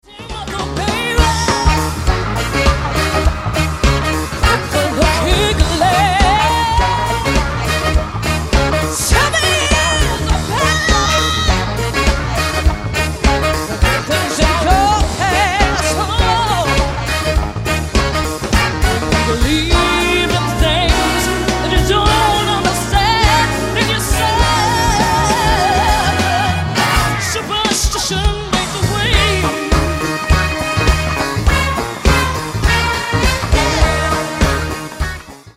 Horns